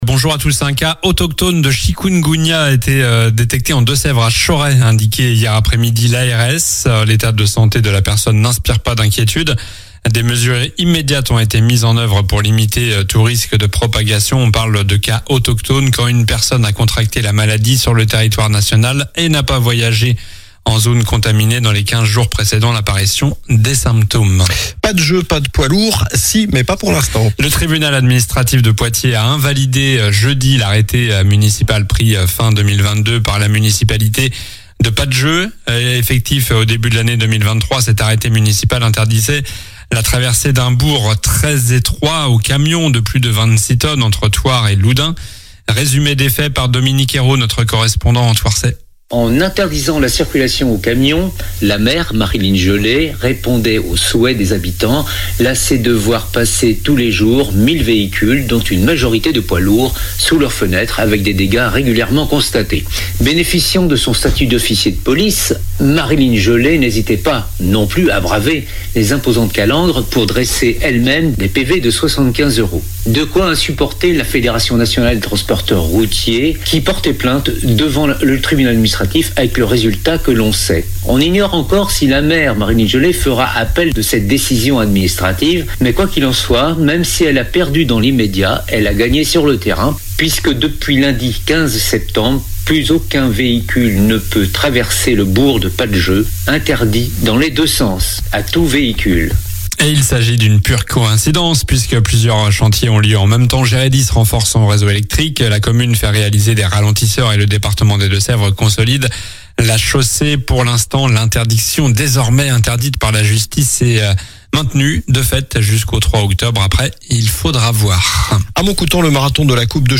Journal du samedi 20 septembre (matin)